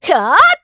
Isolet-Vox_Attack4_kr.wav